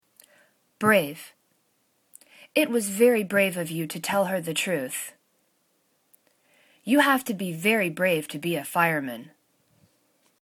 brave  /bra:v/ adj